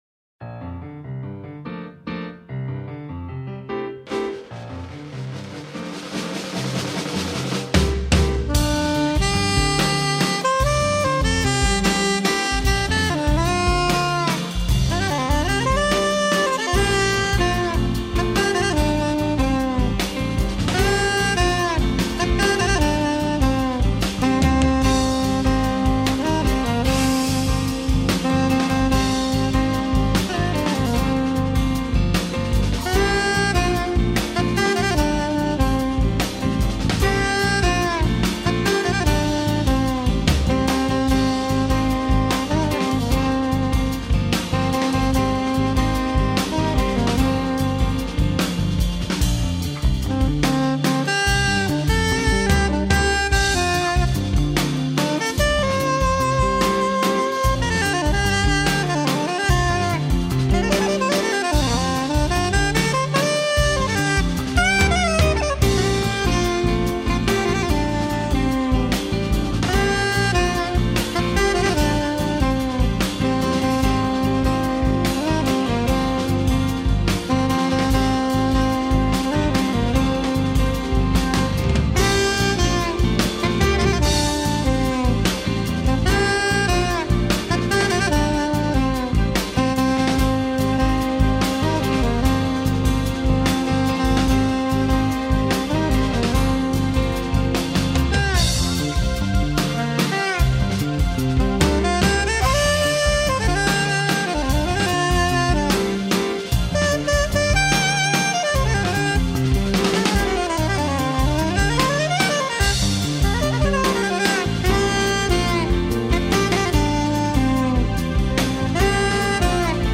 1325   04:12:00   Faixa:     Jazz